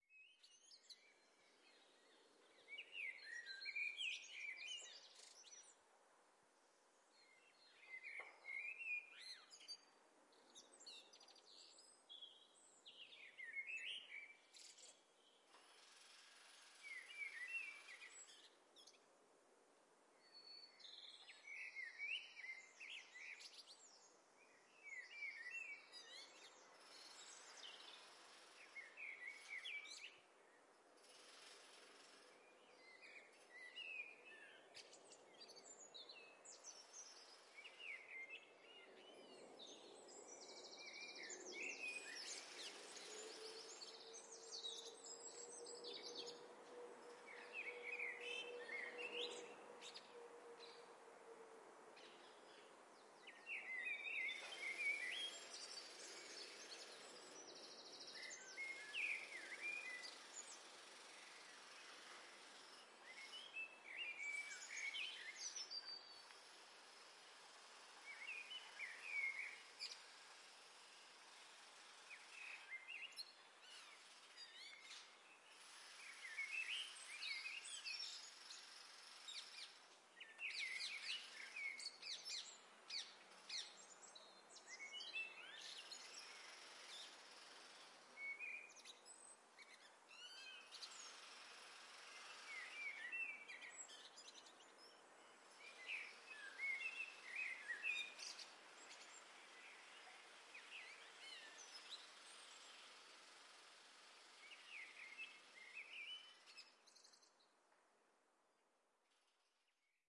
声音素材 " 环境风2（白噪音）
描述：白噪声可以用作环境风声。
标签： 飞机 白噪声 环境 环境 背景 氛围 噪声 弗利
声道立体声